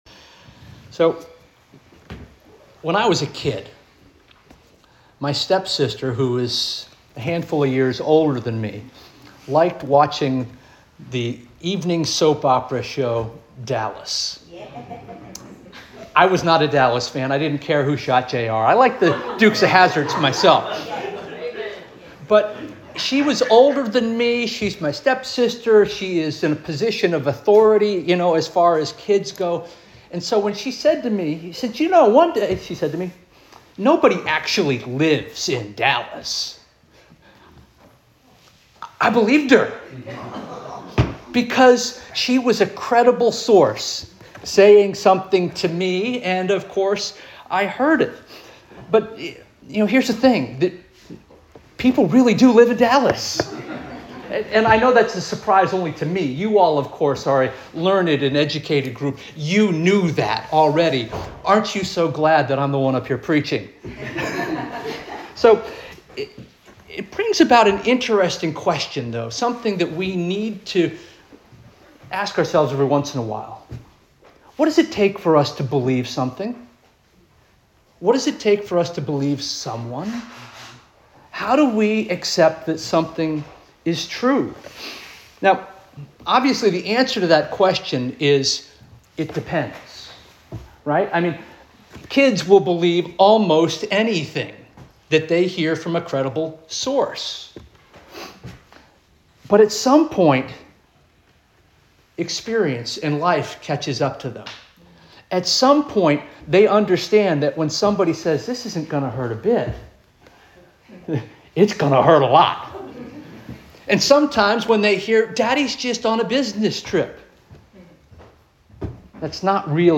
October 26 2025 Sermon - First Union African Baptist Church